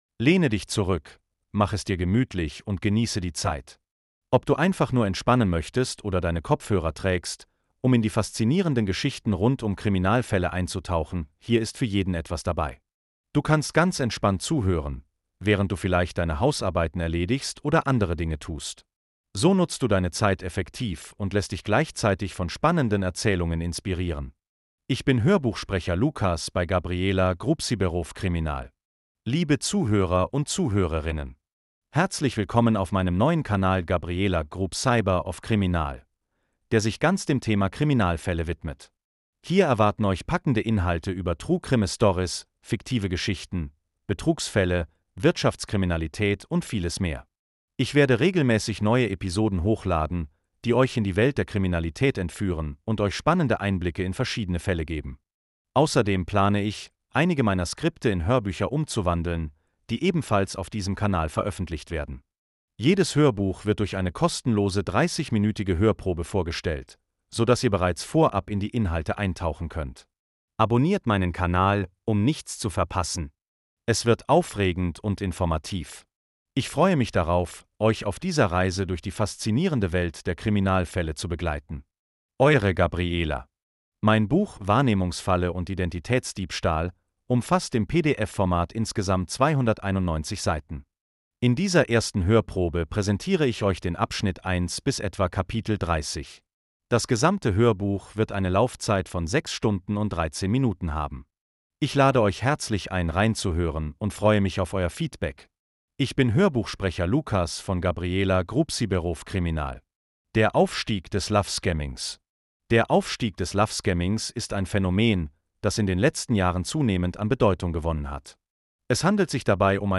Der aufstieg des Lovescammings 30minuten Hörprobe in das Buch Wahrnehmungsfalle & Identitätsdiebstahl - True Crime - Kriminalfälle - Cybercrime - organisierte Kriminalität - auch Fiktion